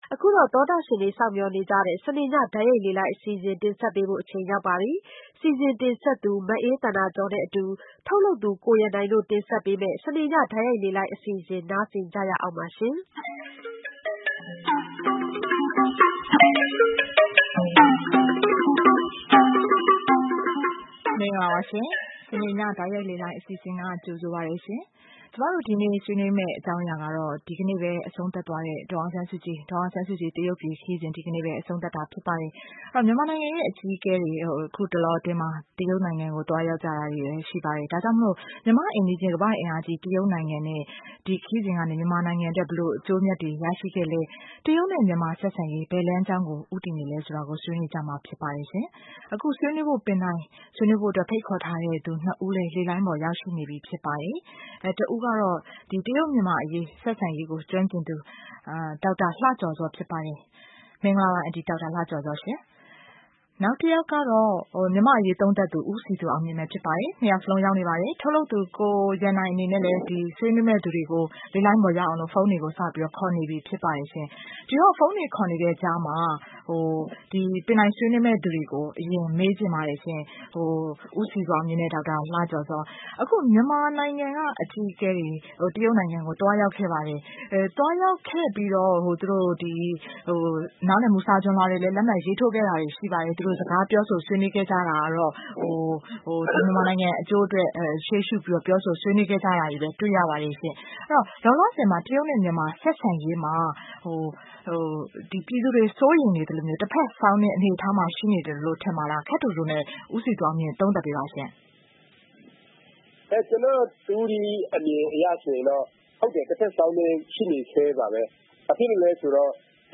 တရုတ်မြန်မာဆက်ဆံရေးလားရာ (စနေည တိုက်ရိုက်လေလှိုင်း)